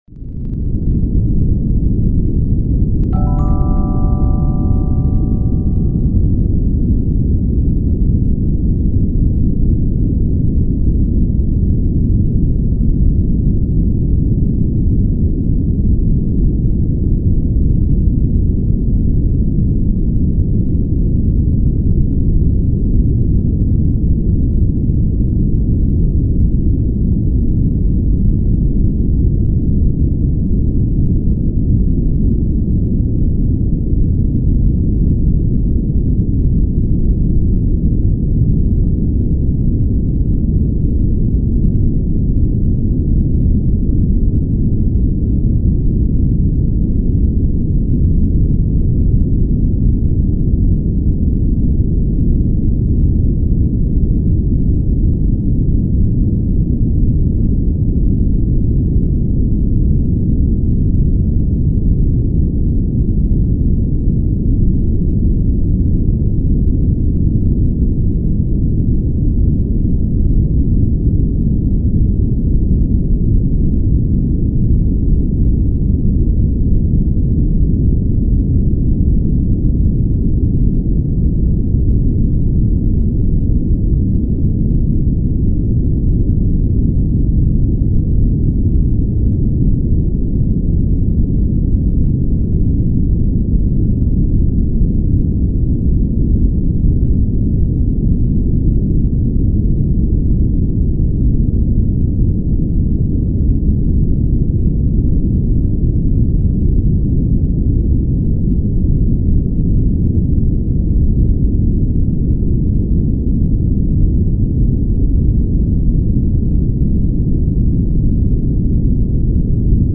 【集中時間】ポモドーロタイマー×ブラウンノイズ 50分×5分休憩